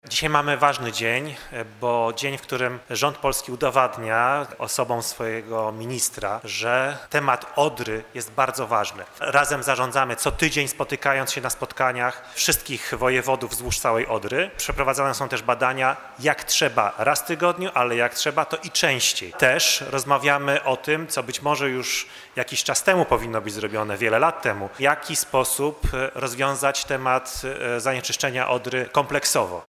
W Dolnośląskim Urzędzie Wojewódzkim odbyła się we wtorek debata Ministerstwa Klimatu i Środowiska z przedstawicielami samorządów oraz podmiotami publicznymi nt. współpracy w zakresie zapobiegania zanieczyszczeniom w rzece Odrze.